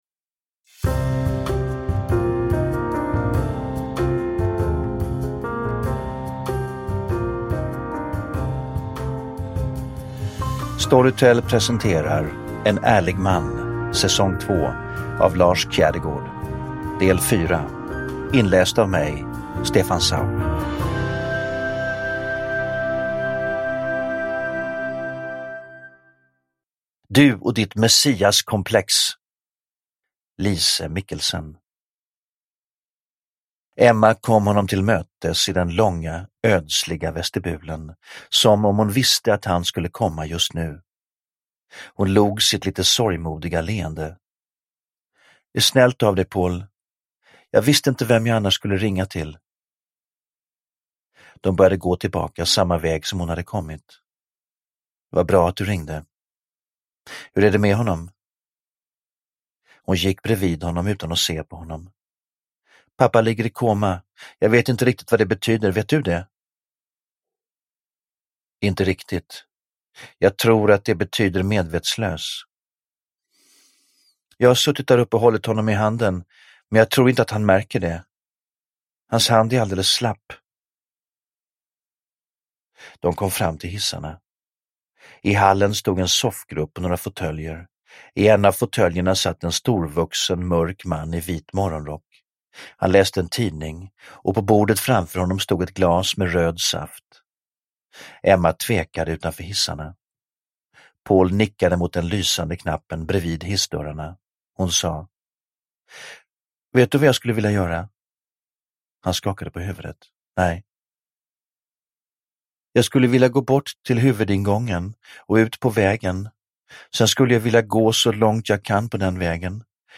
En ärlig man - S2E4 – Ljudbok – Laddas ner
Uppläsare: Stefan Sauk